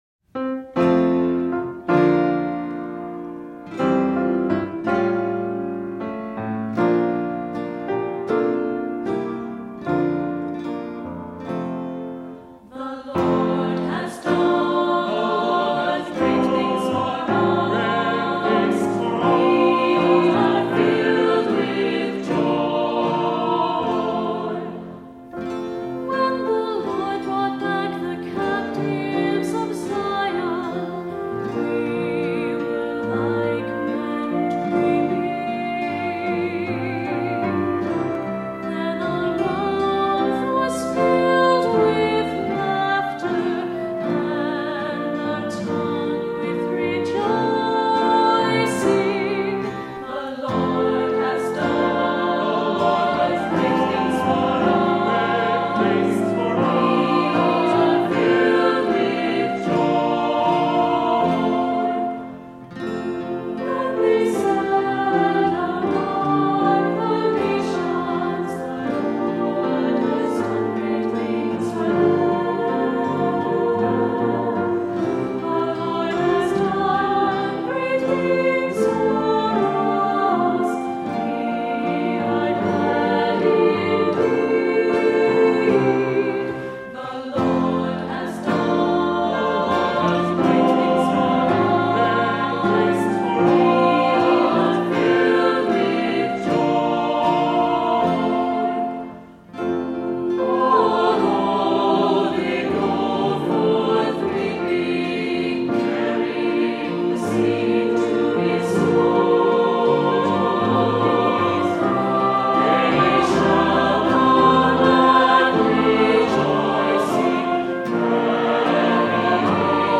Voicing: SAB; Assembly; Cantor